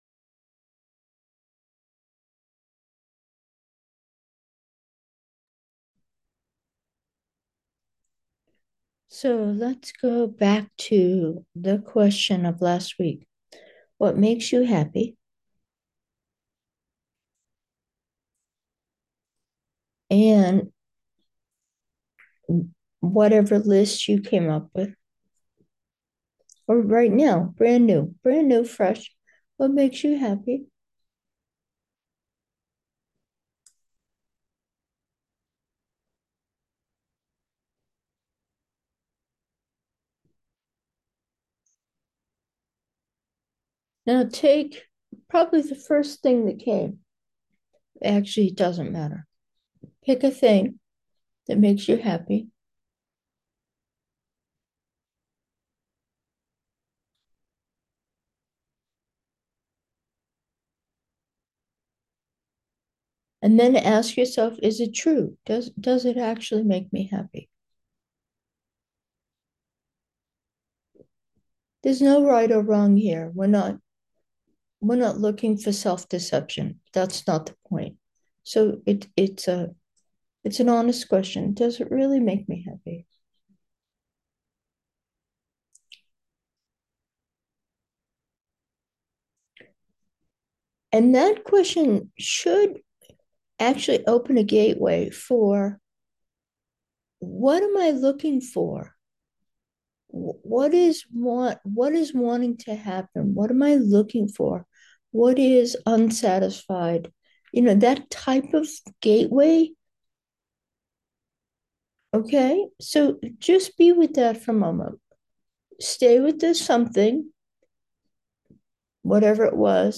Meditation: happy 7, insights